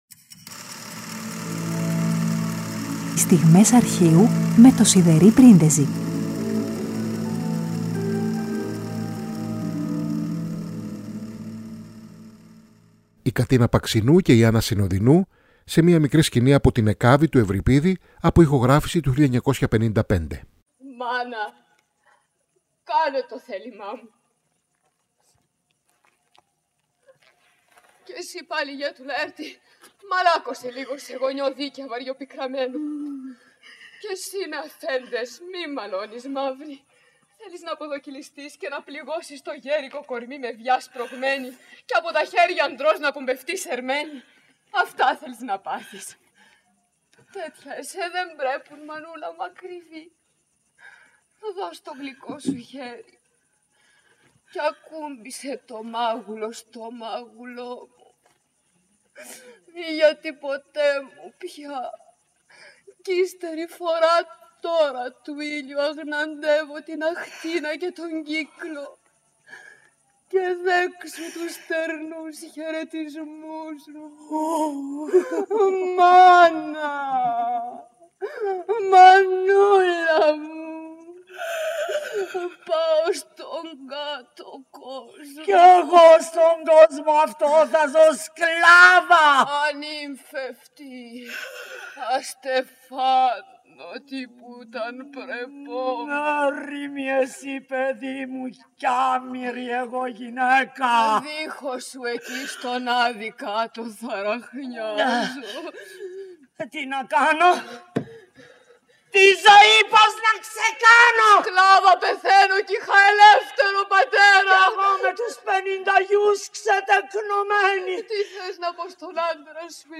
M.Παρασκευή 22 Απριλίου: Η Κατίνα Παξινού και η Άννα Συνοδινού σε μια μικρή σκηνή από την «Εκάβη» του Ευριπίδη από ηχογράφηση του 1955.